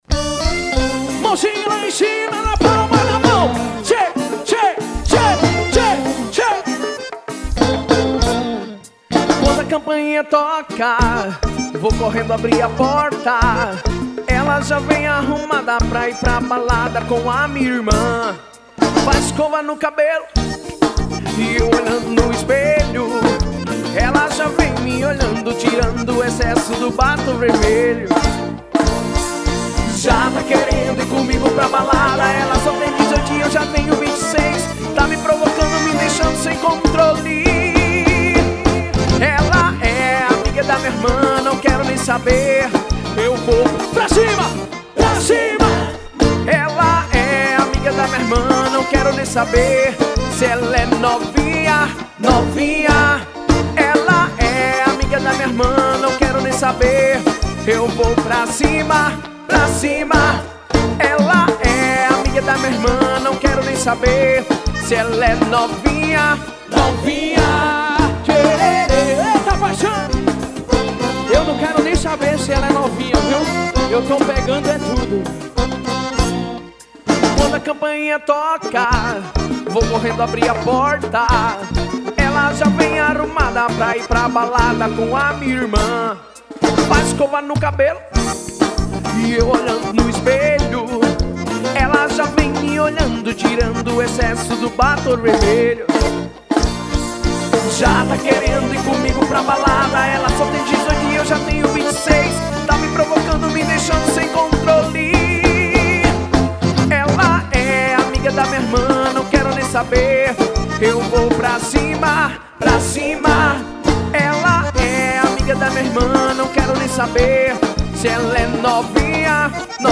Ao Vivo
Sertanejo